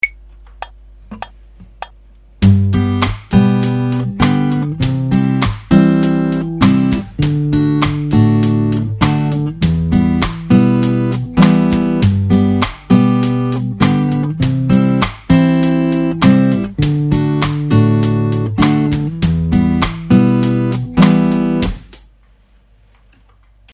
Check out the example below using the same chords with a syncopated rhythm pattern. The IMA fingers will stay in the same position plucking the first 3 strings while the thumb moves plucking the 3 different bass (4th, 5th and 6th) strings.